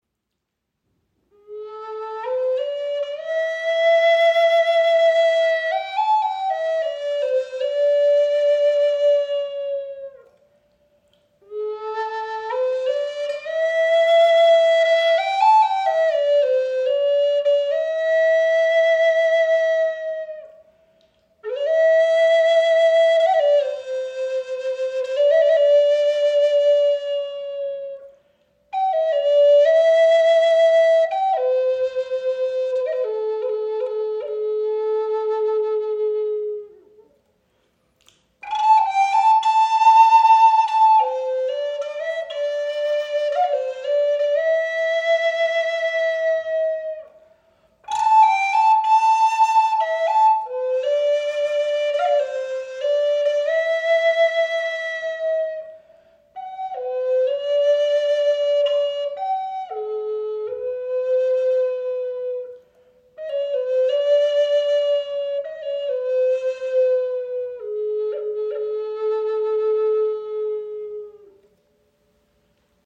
Die Sparrow Hawk Flöte in A-Moll (440 Hz) ist eines unserer beliebtesten Modelle. Sie wird aus einem einzigen Stück spanischer Zeder gefertigt – ein weiches Holz mit warmer Klangfarbe, das klare Höhen und kraftvolle Tiefen erzeugt.